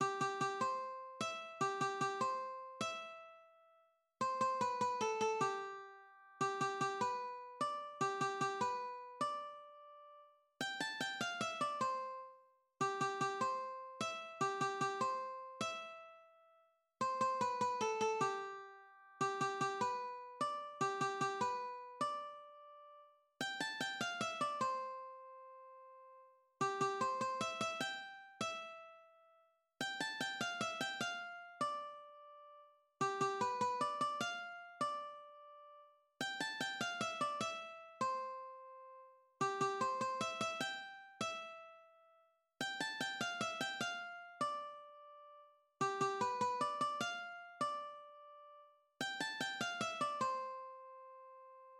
GenereFolk
La cucaracha — Versione per sintetizzatore